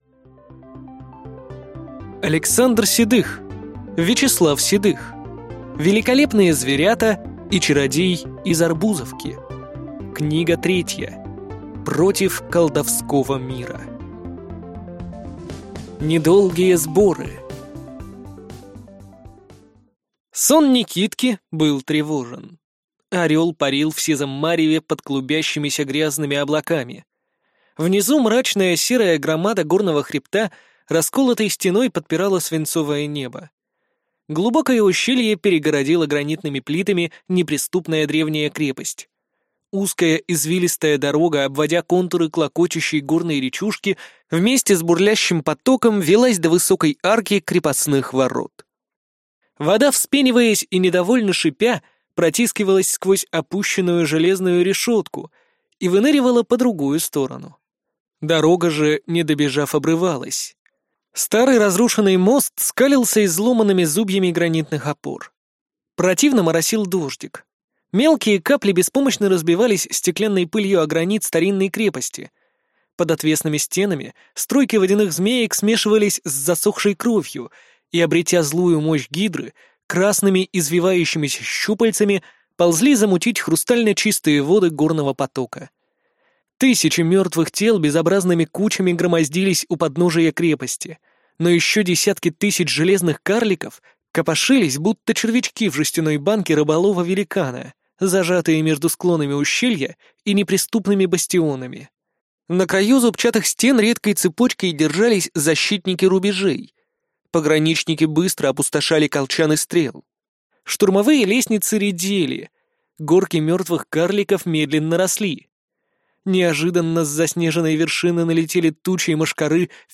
Аудиокнига Великолепные зверята и чародей из Арбузовки. Книга 3. Против Колдовского мира | Библиотека аудиокниг